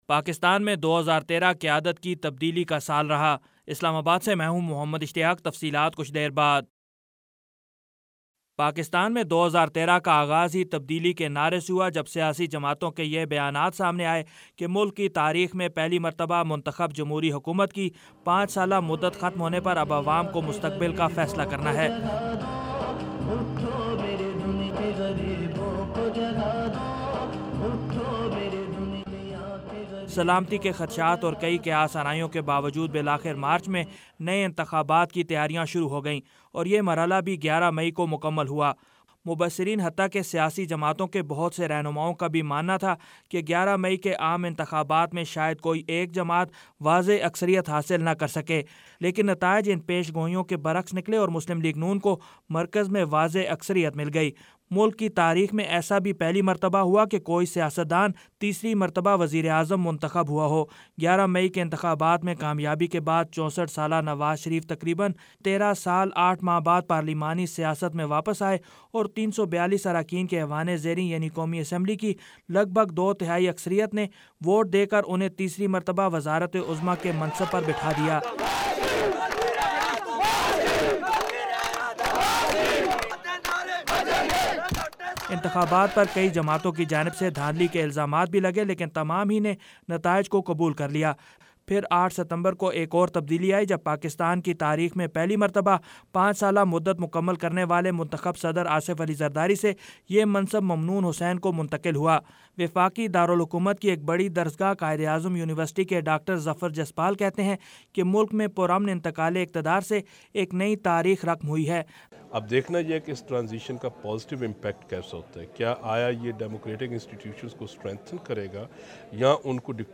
پاکستان میں 2013 قیادت کی تبدیلی کا سال رہا، خصوصی ریڈیو رپورٹ